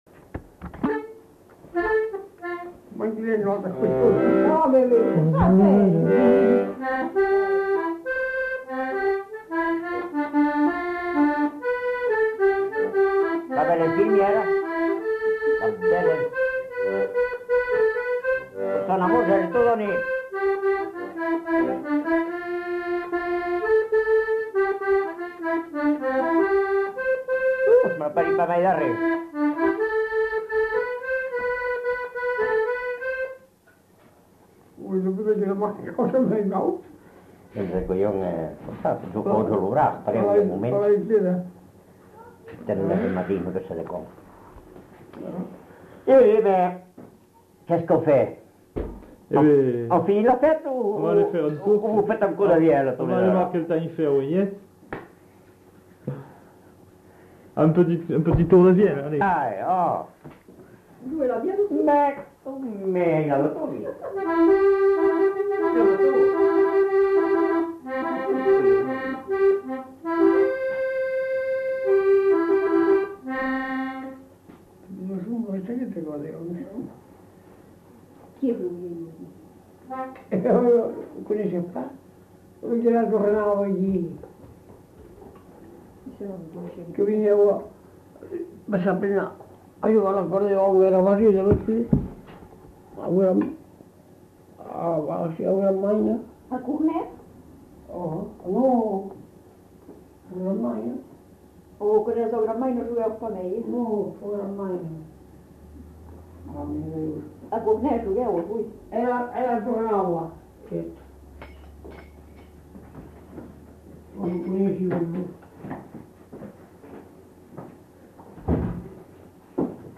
Aire culturelle : Lugues
Lieu : Pindères
Genre : morceau instrumental
Instrument de musique : accordéon diatonique
Danse : valse